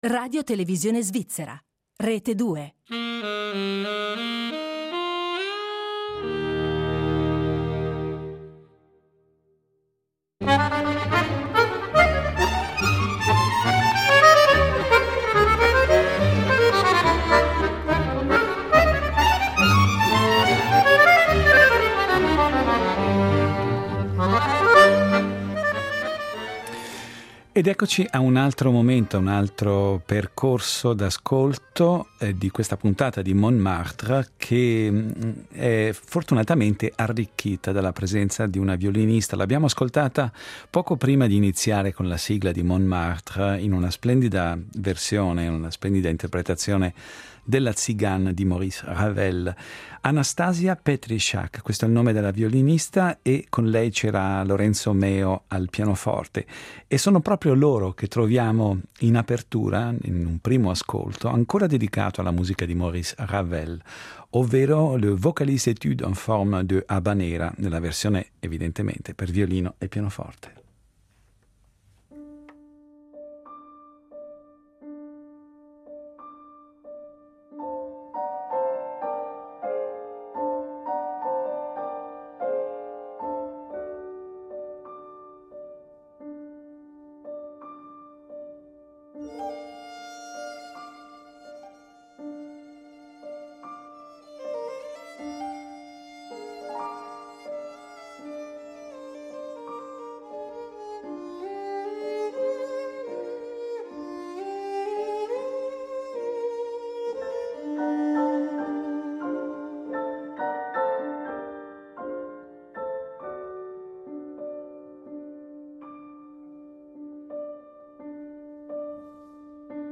Incrontro con la giovane violinista ucraina